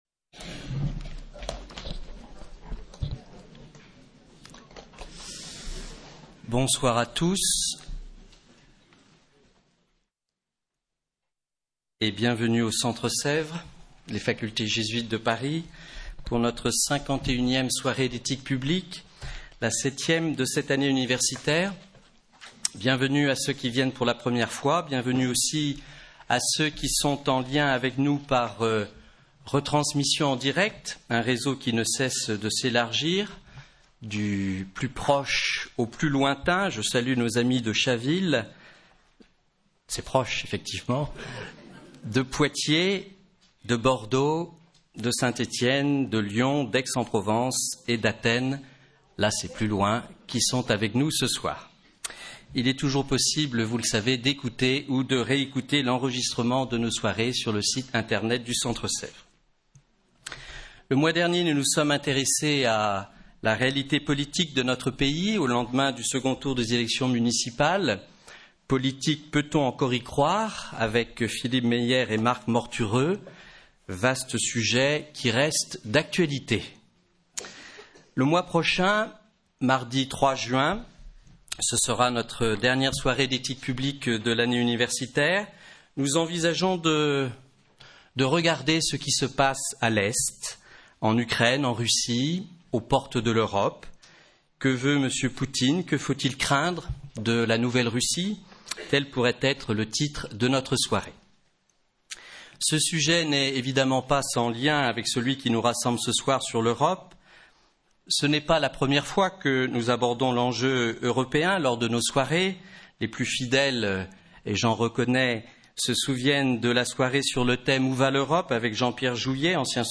Soirée animée